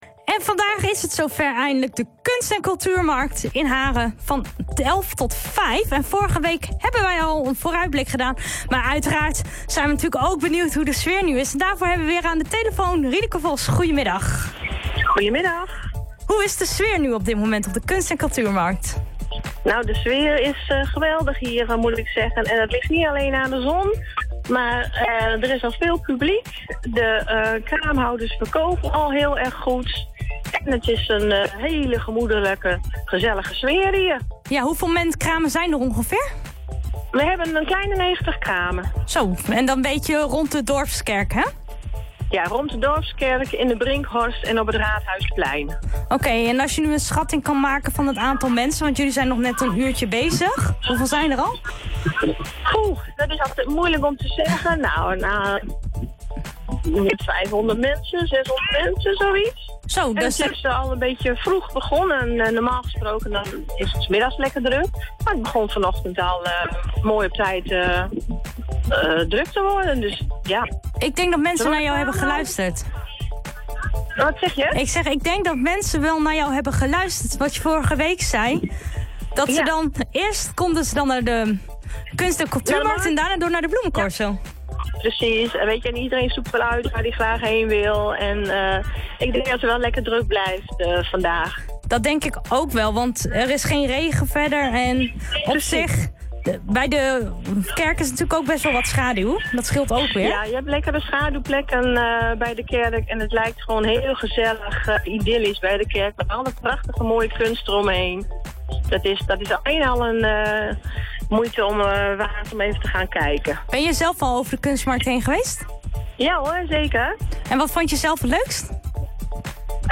Haren Doet op OOG Radio hoorde tijdens haar uitzending hoe het verliep.